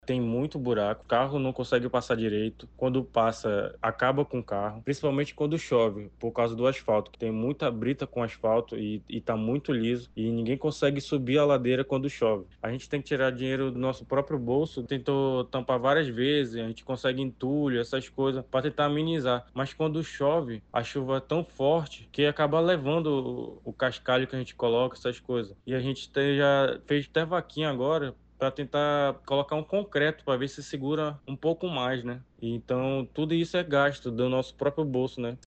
SONORA-RAPAZ_COMUNIDADE.mp3